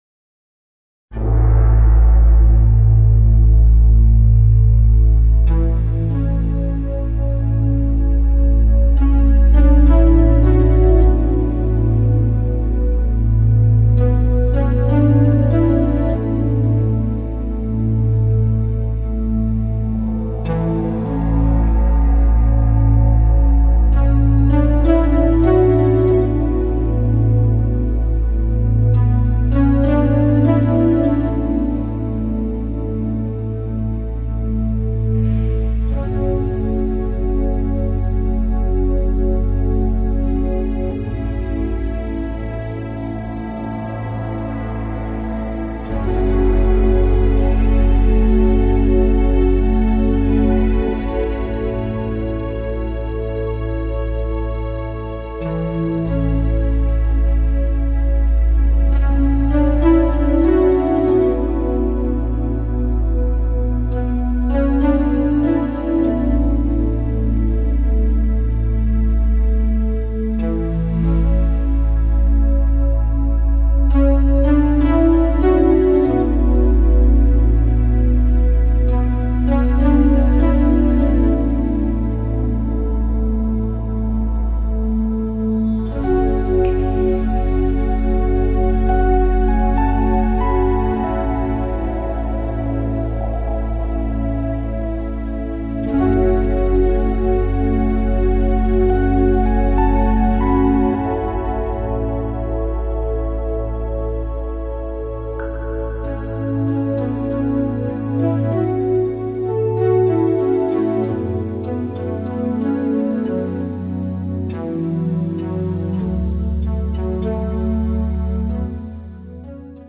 以简单的配乐、和缓的速度，企图给人清楚单一的意象。